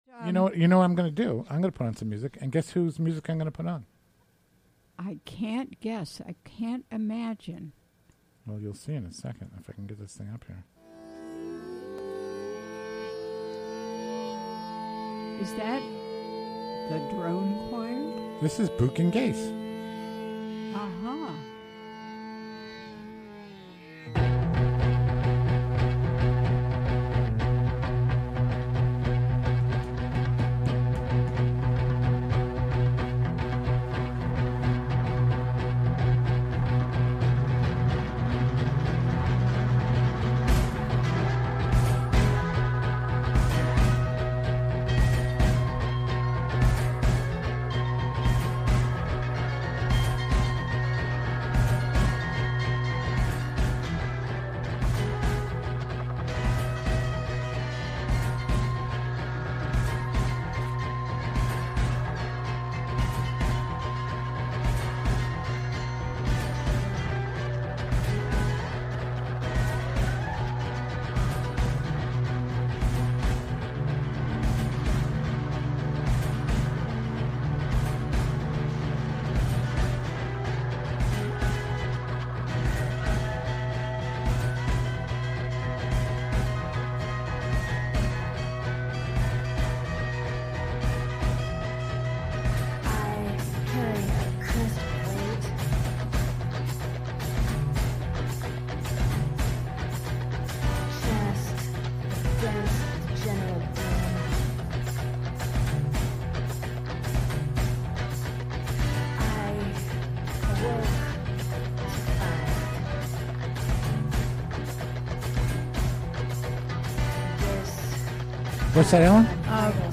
Recorded during the WGXC Afternoon Show on Thursday July 21, 2016.